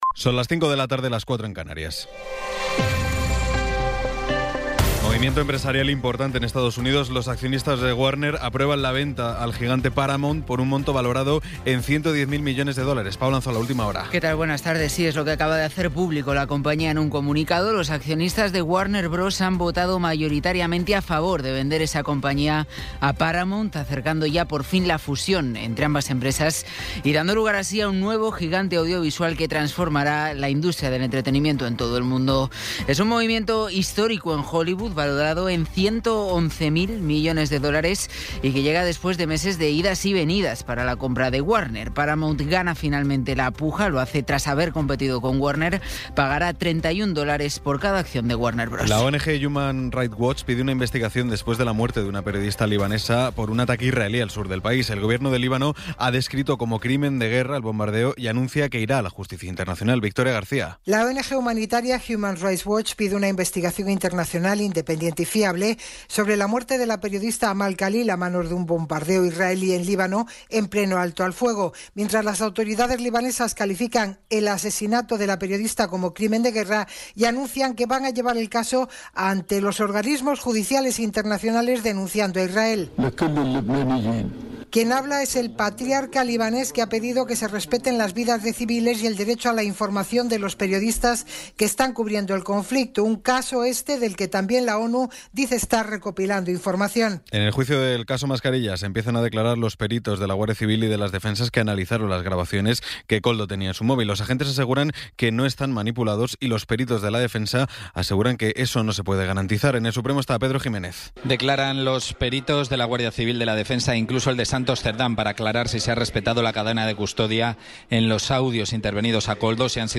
Resumen informativo con las noticias más destacadas del 23 de abril de 2026 a las cinco de la tarde.